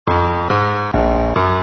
piano nē 81
piano81.mp3